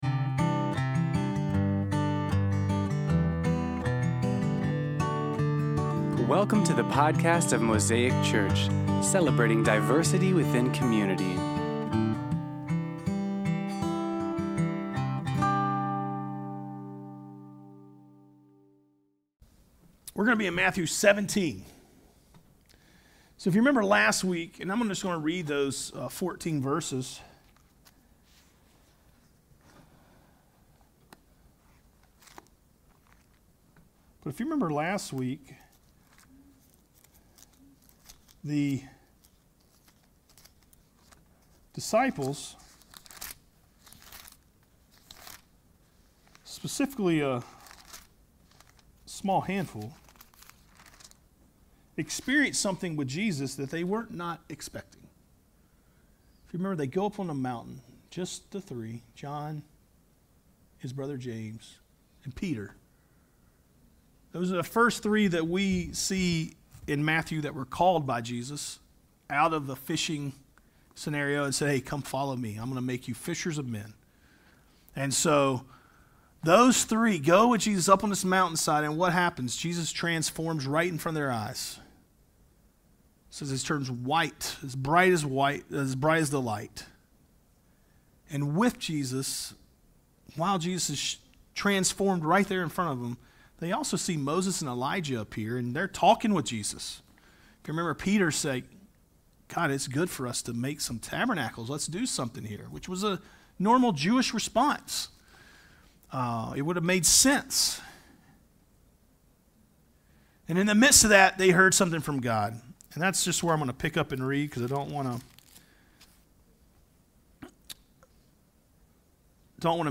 Sermon Series on Matthew's Gospel